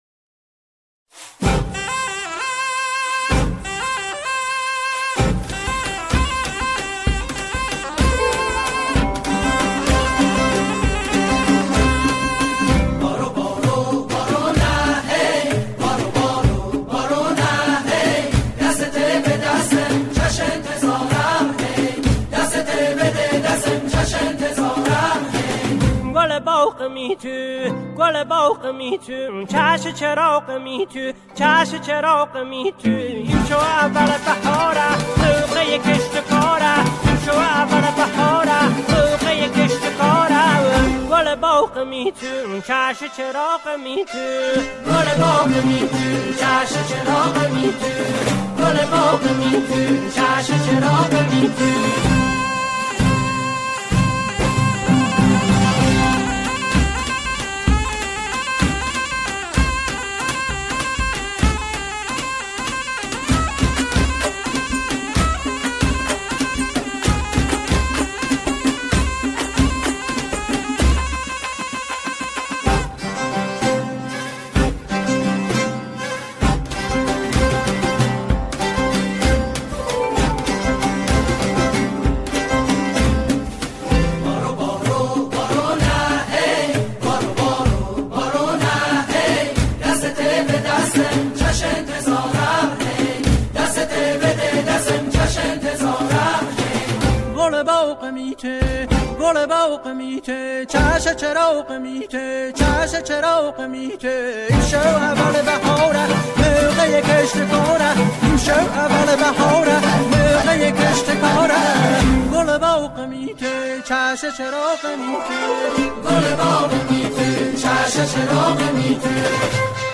با گویش لری اجرا شده است.